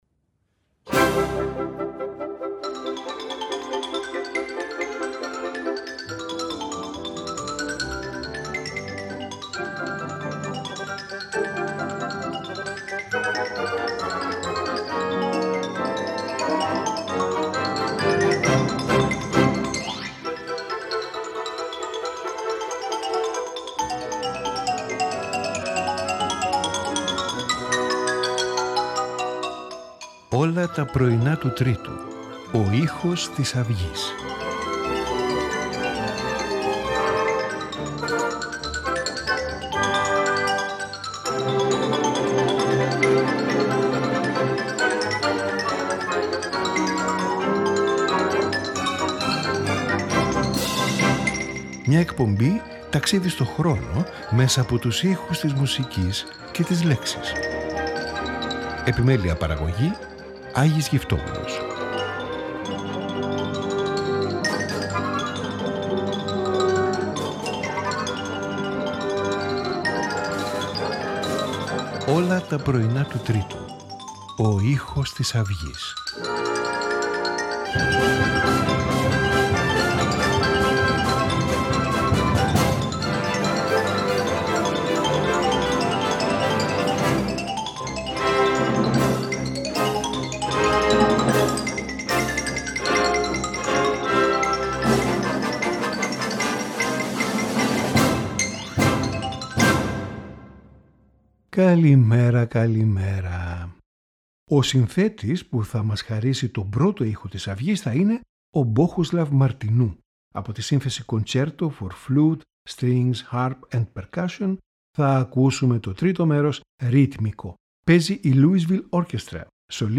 Piano Quintet in E-flat major
Bassoon Concerto in G minor
String Quartet No.14 in C-sharp minor